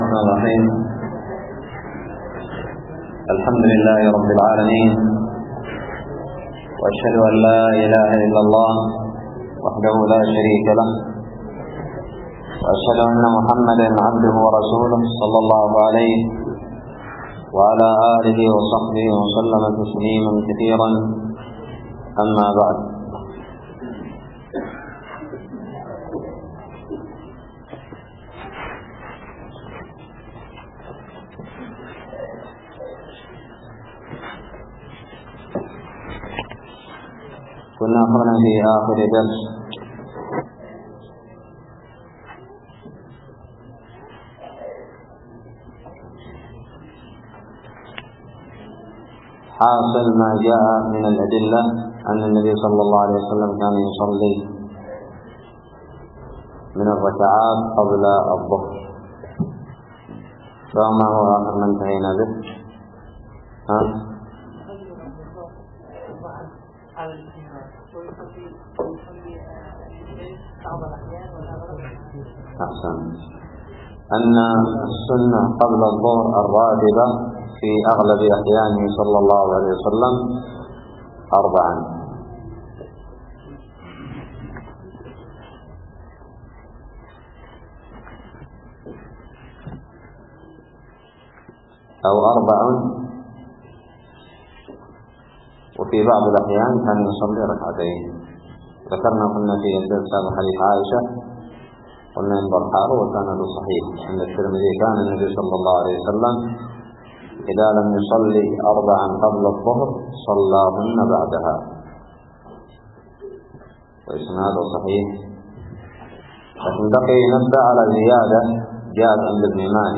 الدرس الثاني والثمانون من كتاب الصلاة من الدراري
ألقيت بدار الحديث السلفية للعلوم الشرعية بالضالع